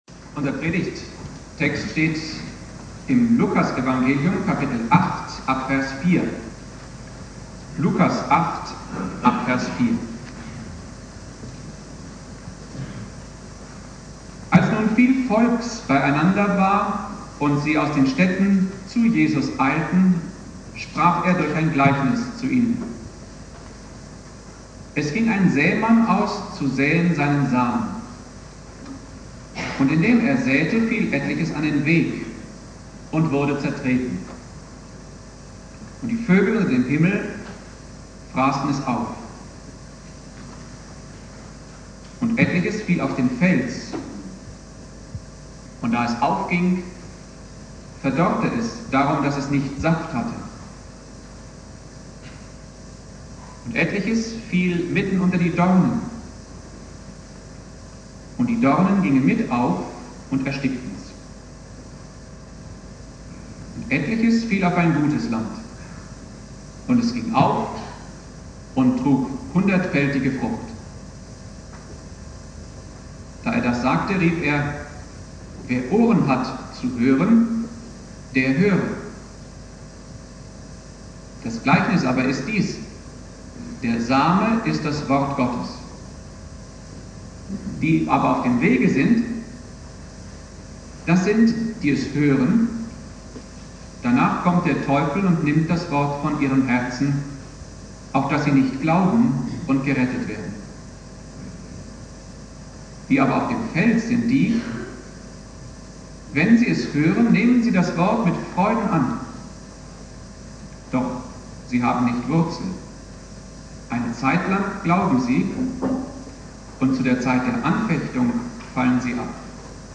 Thema: "Der vierfache Acker" (mit Außenmikrofon aufgenommen) Bibeltext